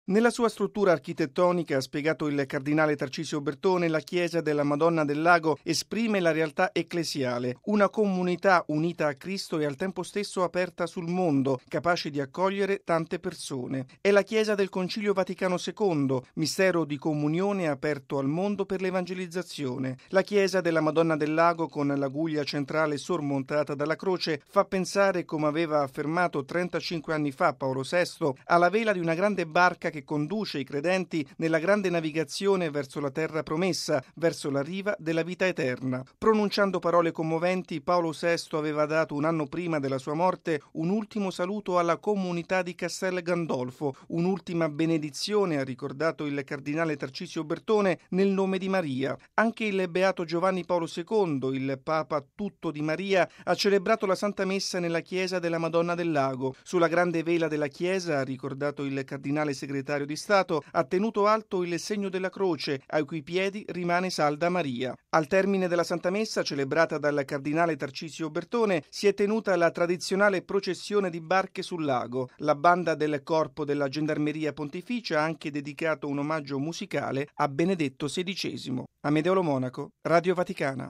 ◊   A Castel Gandolfo la tradizionale processione di barche, al seguito della statua della Vergine, è stata preceduta ieri pomeriggio dalla Santa Messa celebrata dal cardinale segretario di Stato, Tarcisio Bertone, in occasione del 35.mo anniversario dell’inaugurazione della chiesa della Madonna del Lago. Il servizio